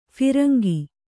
♪ phirangi